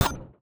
UIClick_Menu Mallet Metal Hollow 02.wav